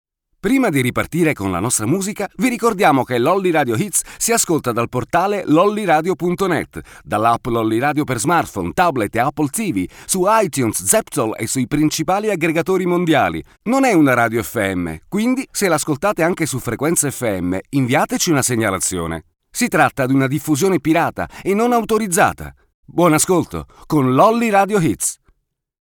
Two words about my home studio. Located in a 8th century stone house, acoustically isolated with stone walls (about 1 meter) and entirely covered with 8cm width panels. Hardware: 16core Mac Pro for recording and editing (audio/video and a 27inches iMac for Skype calls, Hangout, etc…), Scarlett Focusrite. Microphones: Neumann TLM 103 ), Behringer B2 Pro as a secondary one
Sprechprobe: Industrie (Muttersprache):